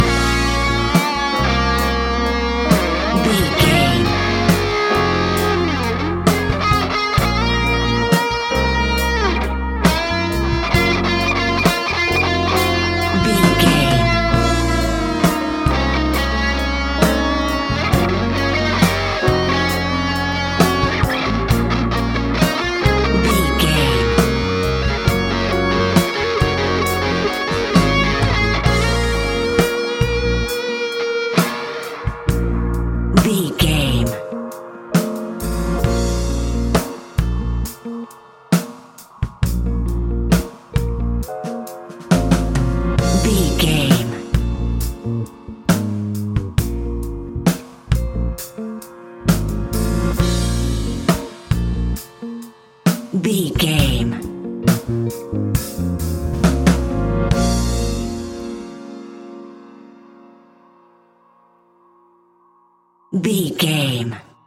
Epic / Action
Fast paced
In-crescendo
Uplifting
Ionian/Major
A♯
hip hop